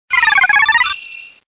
Cri de Korillon dans Pokémon Diamant et Perle.